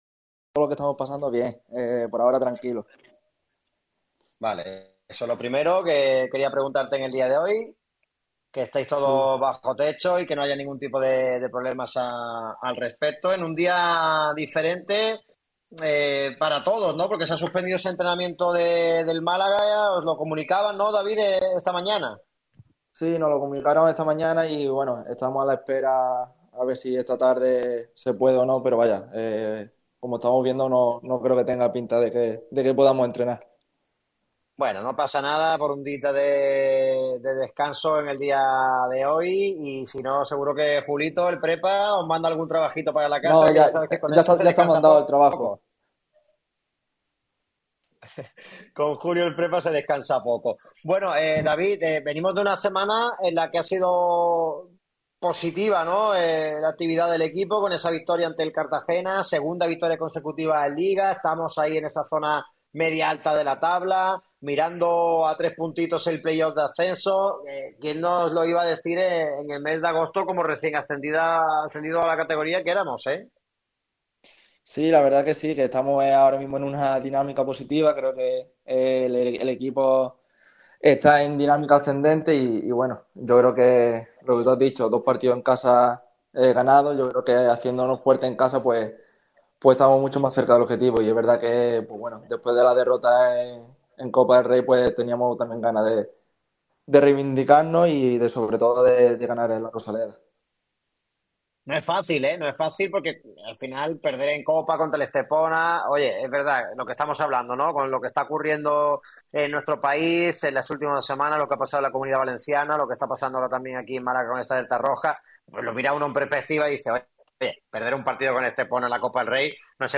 Lee y escucha aquí la entrevista al jugador del Málaga CF, David Larrubia, en exclusiva en Radio MARCA Málaga.
El jugador del Málaga CF, David Larrubia, ha atendido al micrófono rojo de Radio MARCA Málaga en una entrevista exclusiva.